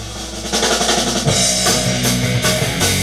It's heavy and fast!
This just punches you right in the face!